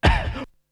Beatbox 2.wav